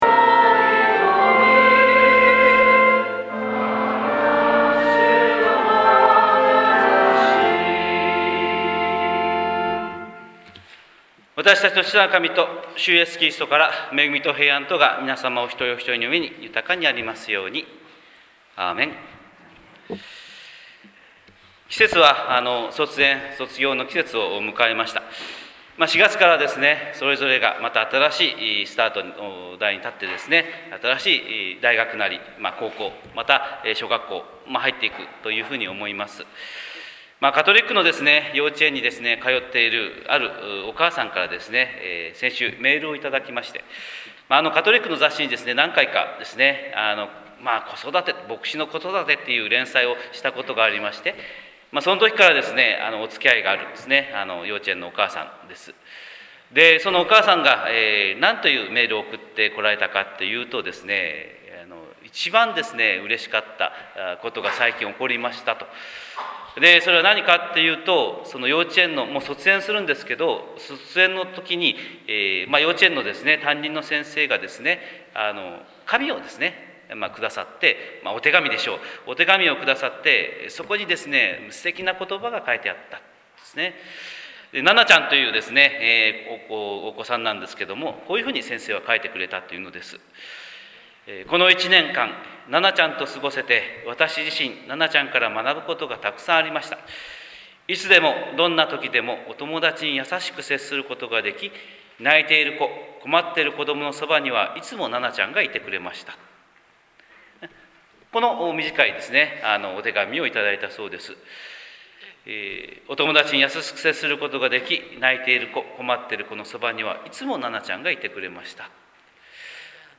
神様の色鉛筆（音声説教）: 四旬節第４主日礼拝160306